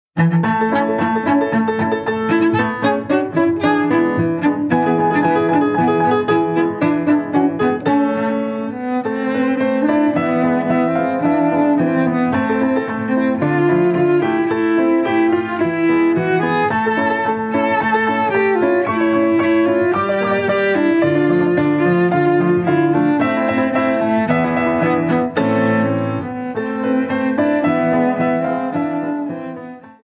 GRABADO EN planet estudio, Blizz producciónes
SOPRANO
VIOLONCELLO SOLO Y ENSAMBLES